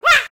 duck_shooted.m4a